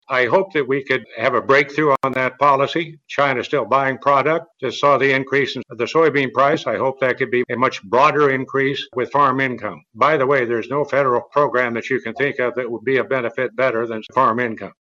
Senate Ag Committee Chair Pat Roberts, speaking during an Agri-Pulse event, will not seek re-election this year.